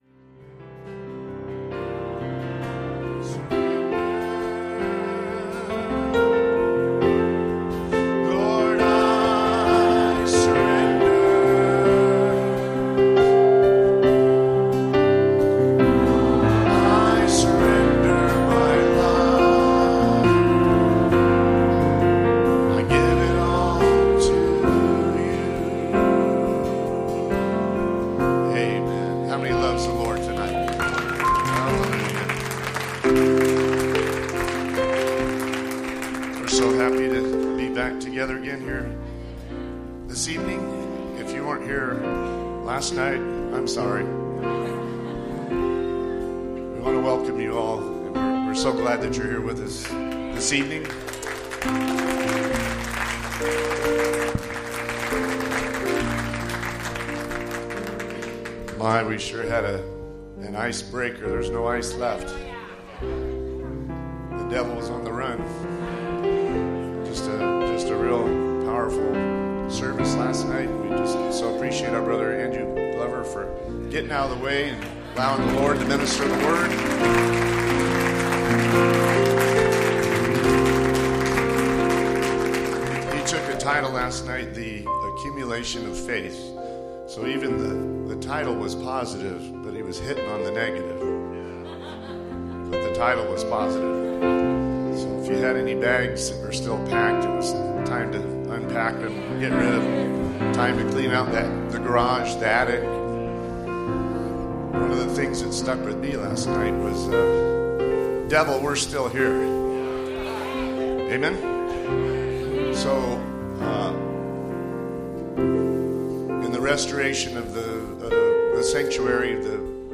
2020 Southern Arizona Fellowship Meetings Tagged with English